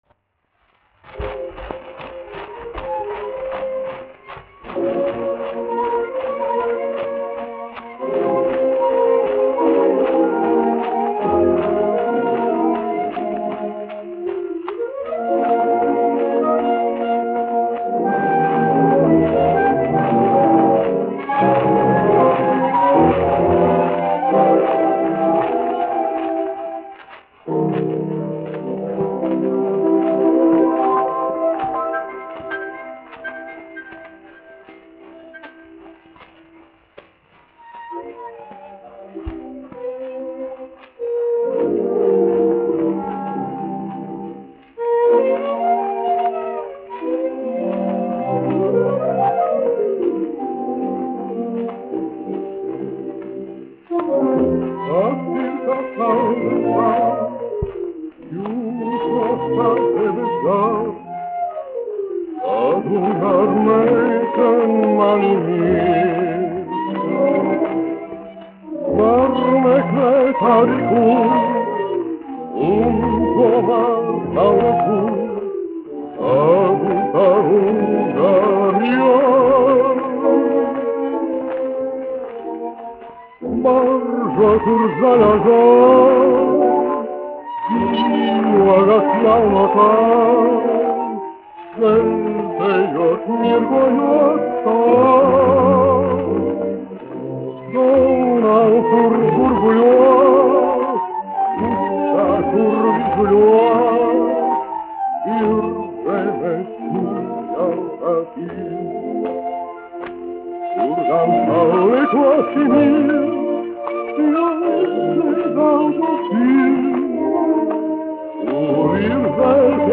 1 skpl. : analogs, 78 apgr/min, mono ; 25 cm
Deju mūzika -- Ungārija
Populārā mūzika
Skaņuplate